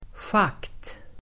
Ladda ner uttalet
Uttal: [sjak:t]